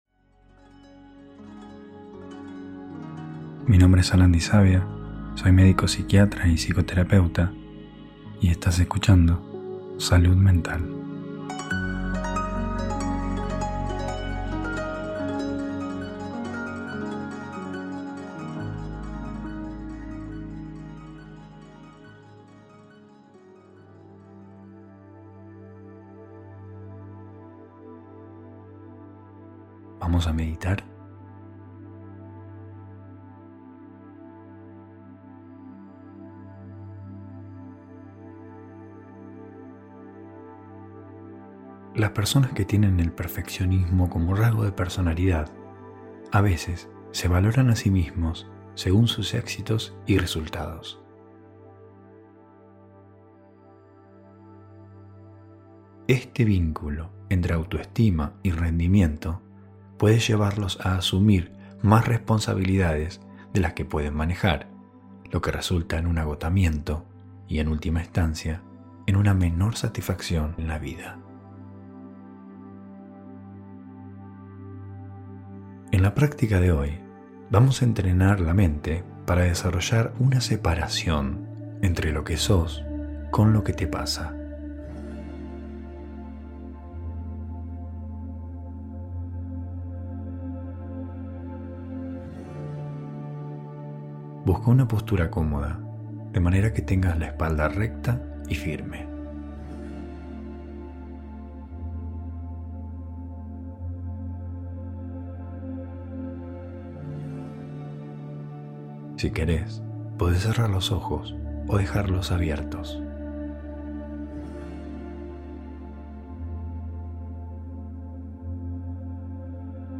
En esta meditación aprenderás que lo que te pasa no define quién sos.